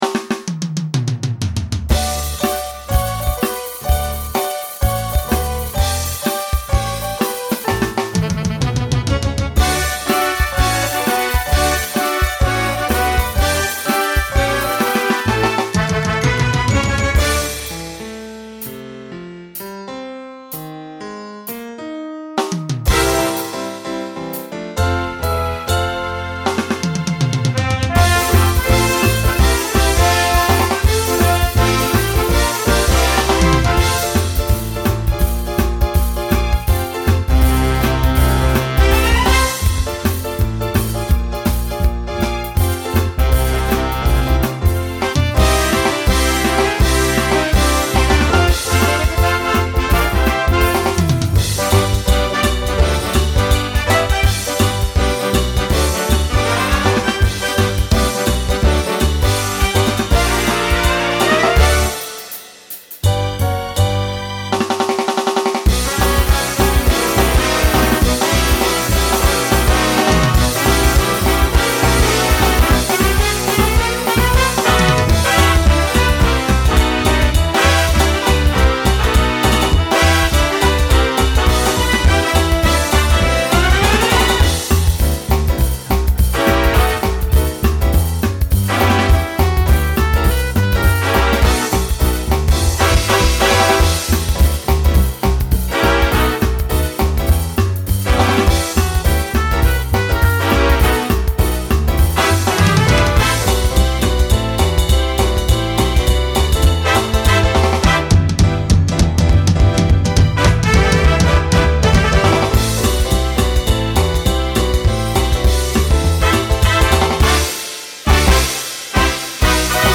New SSA voicing for 2020
Broadway/Film , Swing/Jazz